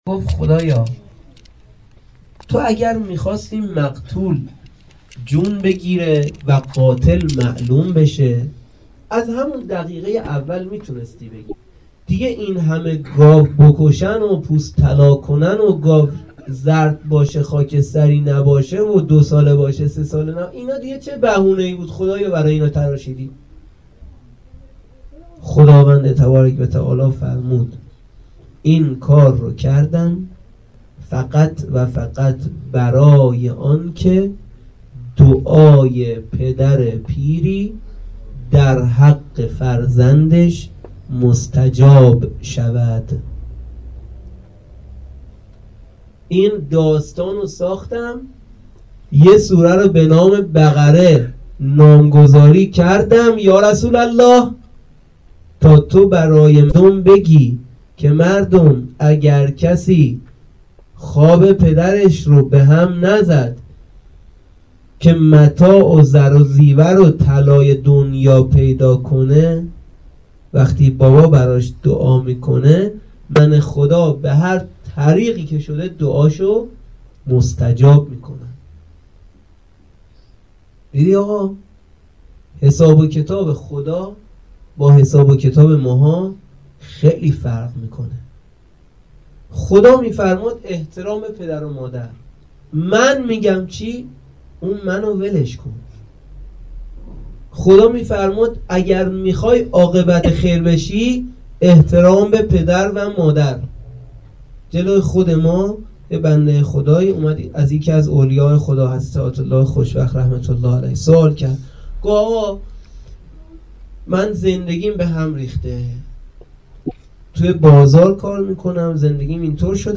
1-sokhanrani.mp3